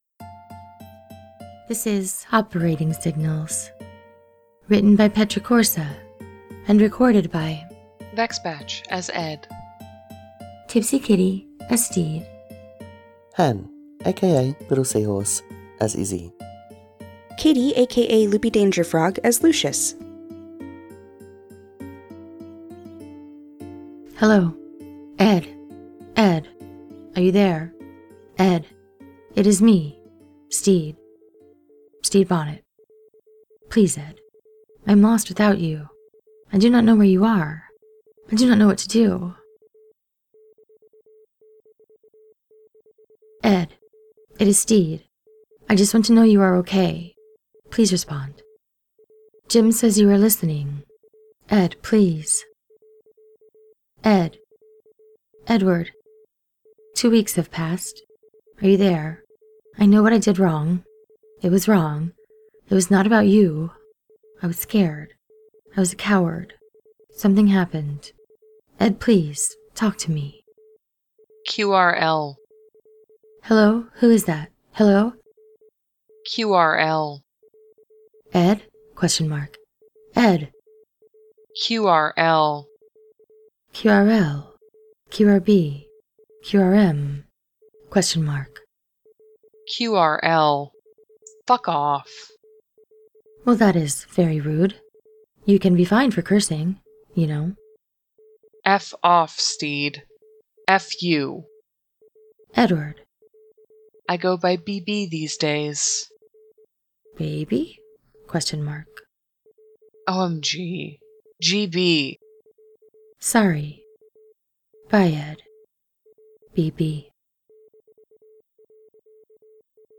collaboration|ensemble
with music/sfx: